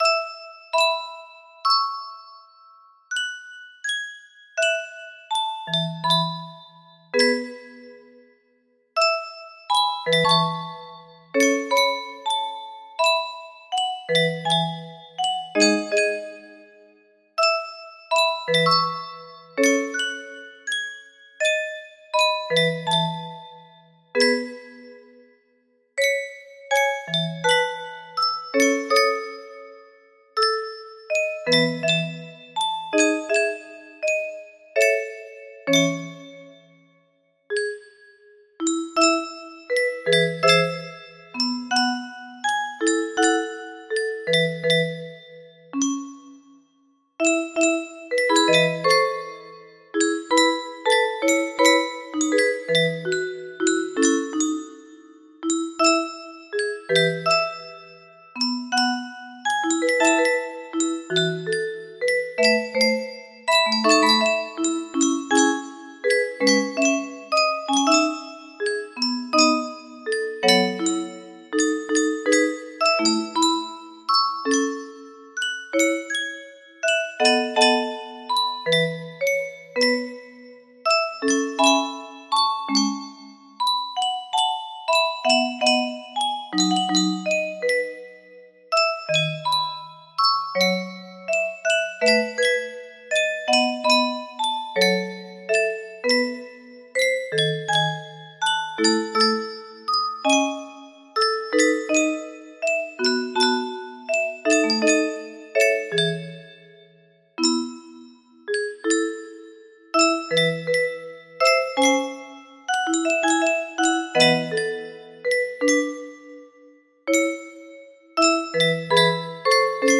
Full range 60
Majored that in the key of A then SLOWED IT ALL DOWN!?